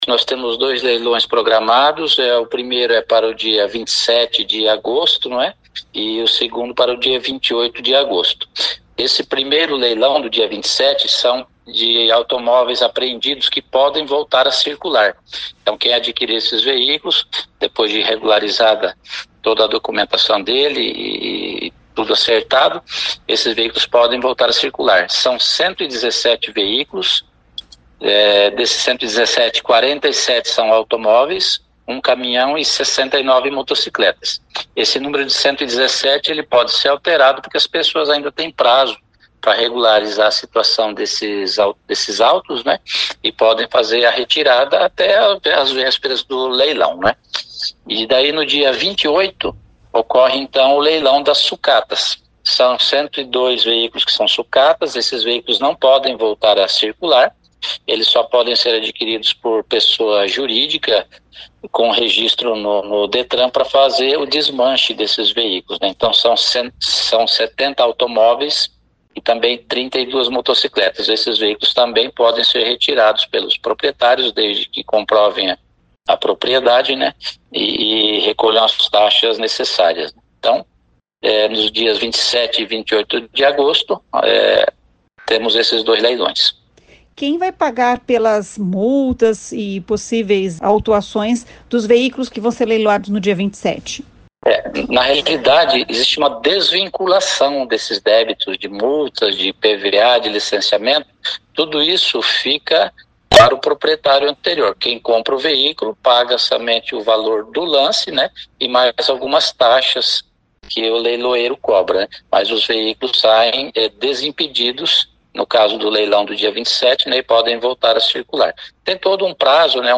Ouça o que diz o secretário de Mobilidade Urbana Gilberto Purpur: